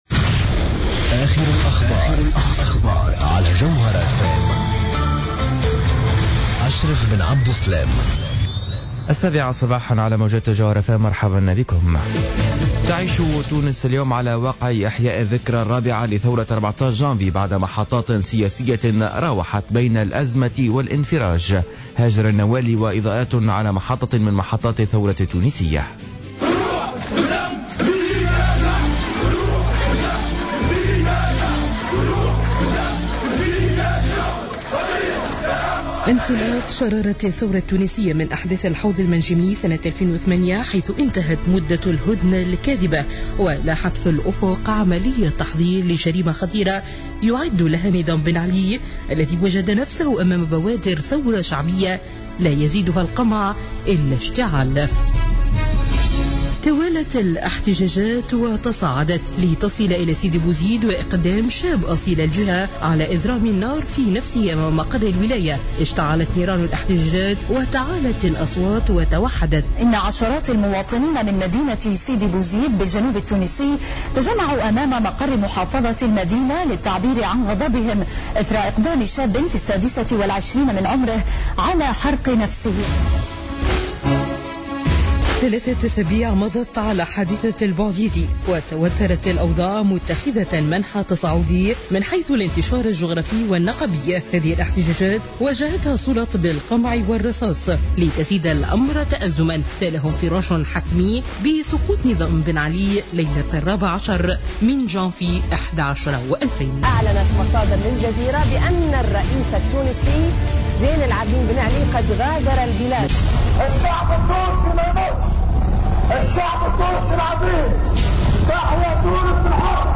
نشرة أخبار السابعة صباحا ليوم الإربعاء 14 جانفي 2014